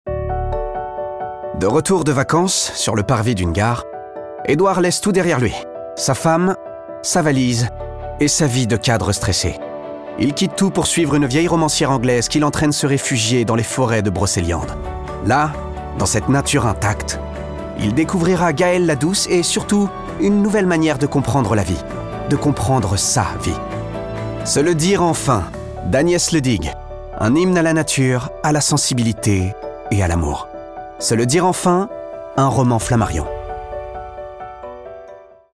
Pub Flammarion